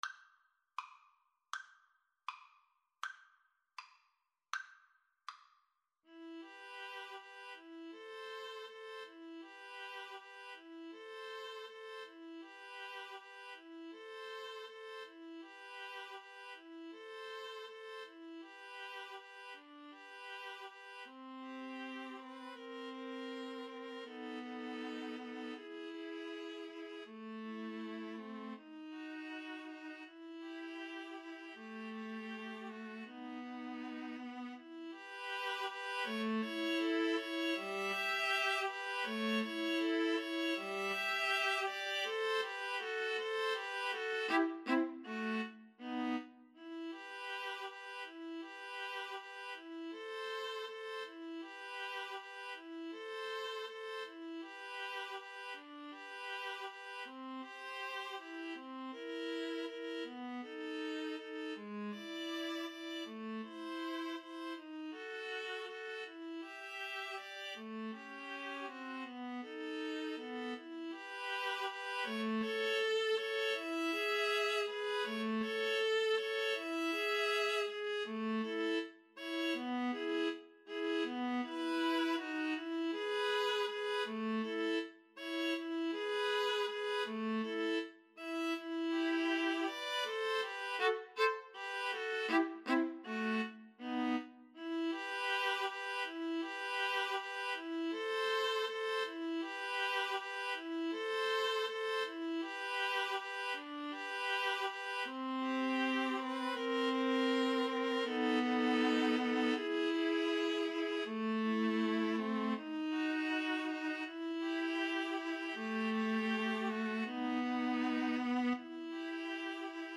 E minor (Sounding Pitch) (View more E minor Music for Viola Trio )
~ = 100 Andante
Classical (View more Classical Viola Trio Music)